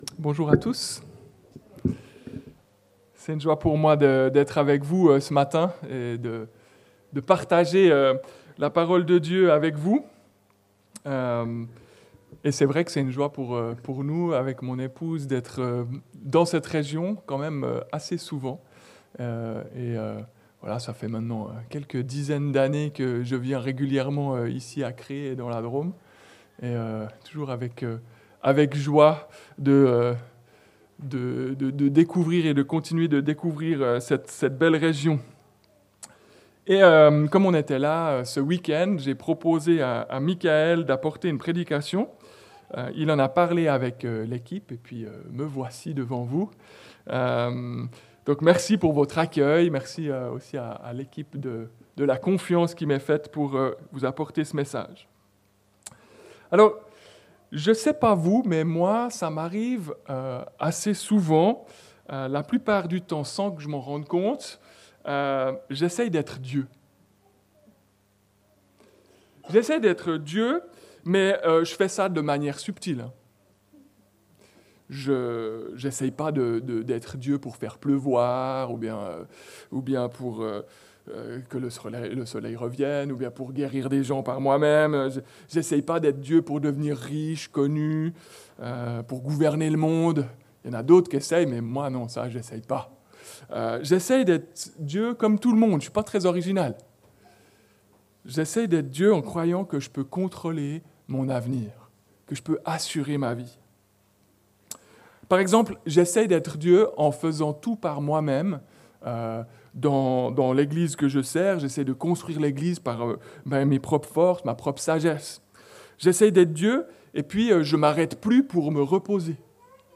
Arrête et laisse Dieu être Dieu - Prédication de l'Eglise Protestante Evangélique de Crest sur le livre de l'Exode